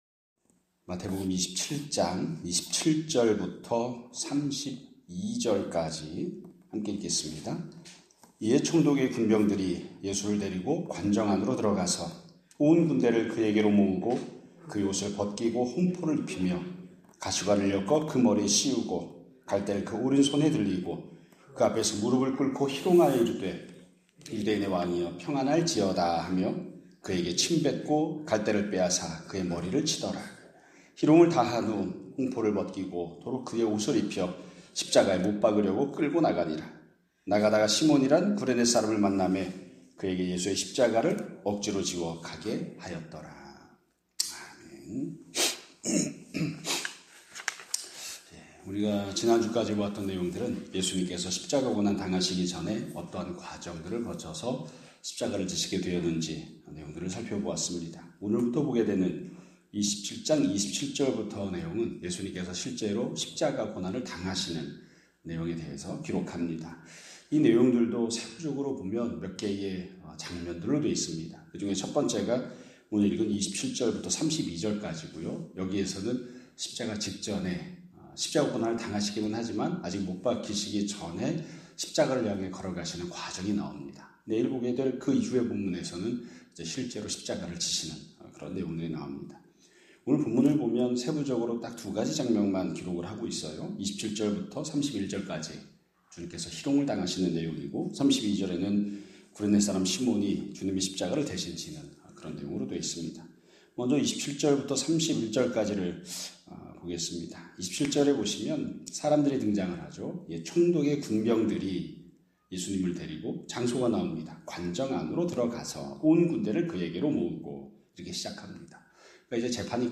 2026년 4월 20일 (월요일) <아침예배> 설교입니다.